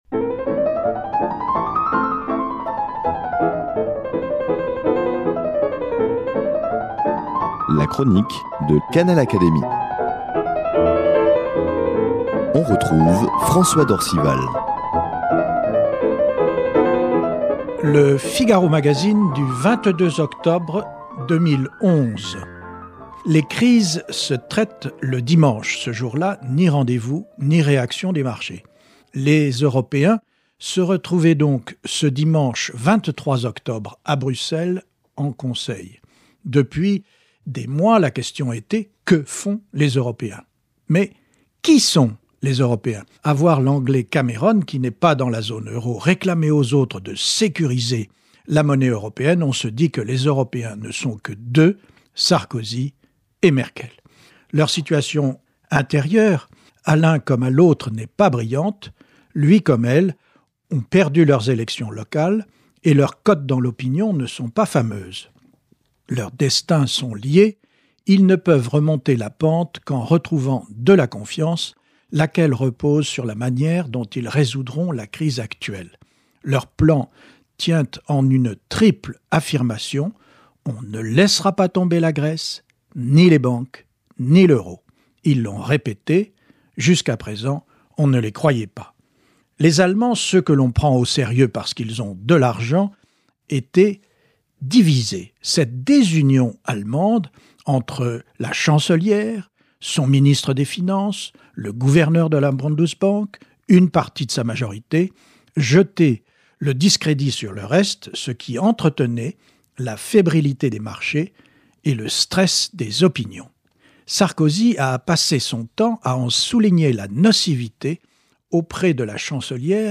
Plus que jamais d’actualité... la chronique de François d’Orcival
Elle est reprise ici par son auteur, avec l’aimable autorisation de l’hebdomadaire.